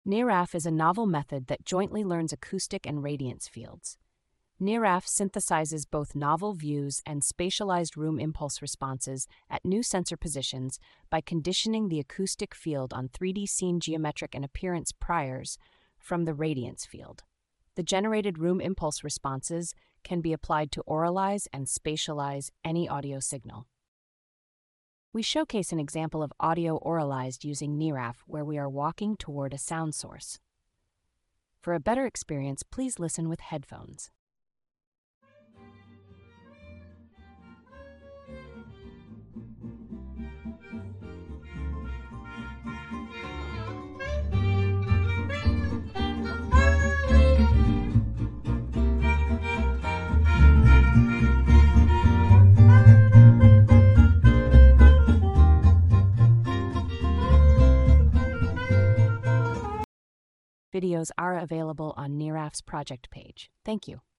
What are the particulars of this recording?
It synthesizes both novel views and room impulse responses at new positions. This audiocarnet, produced for the GDR IASIS day (CNRS), showcases examples of audio generated with NeRAF.